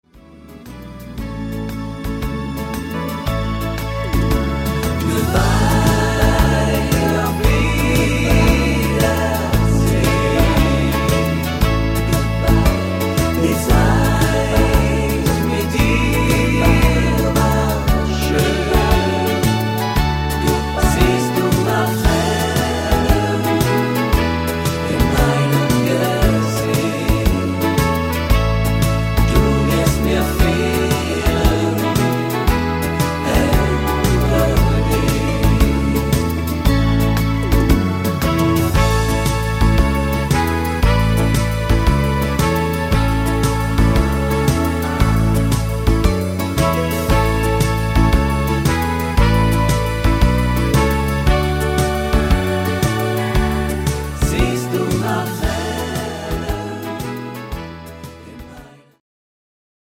Slowfox Mix